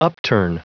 Prononciation du mot upturn en anglais (fichier audio)
Prononciation du mot : upturn